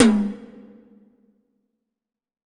WTOM 6.wav